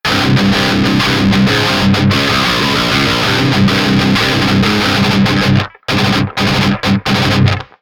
Я когда то Shreddage 2 тестил, мне очень зашло. Но там самому всё рисовать и это только DI, то есть, дисторшн отдельно наваливать придётся.